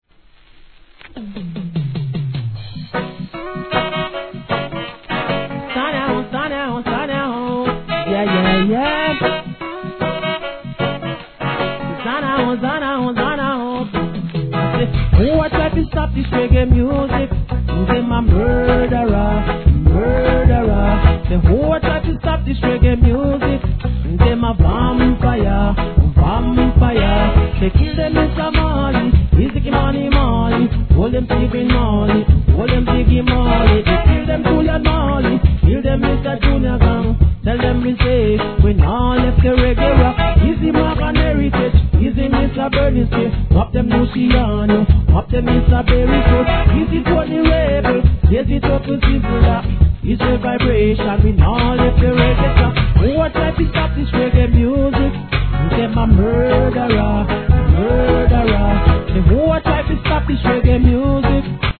¥ 550 税込 関連カテゴリ REGGAE 店舗 数量 カートに入れる お気に入りに追加 2006年の特大BOMB!!